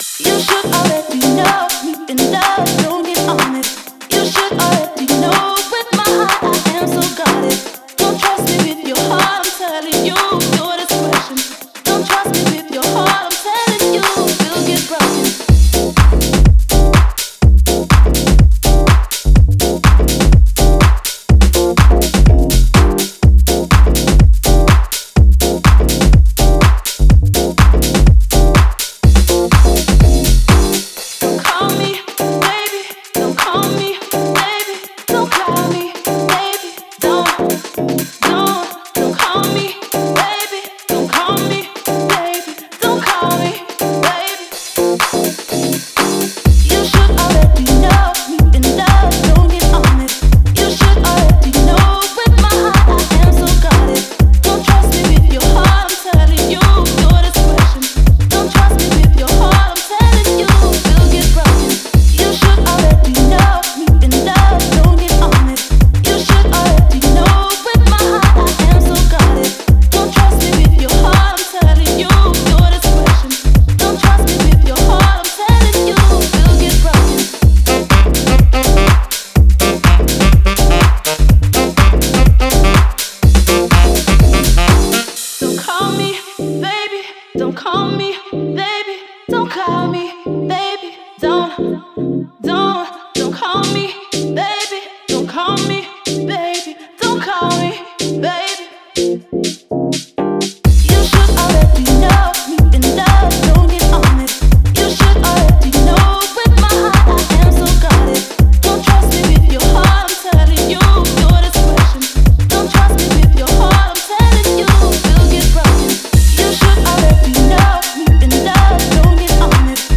это песня в жанре поп-рок